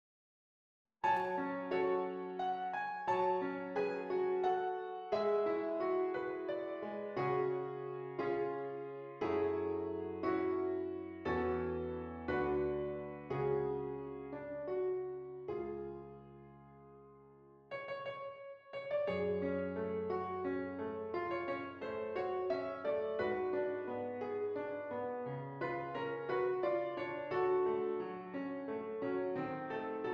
F Sharp Minor
Andante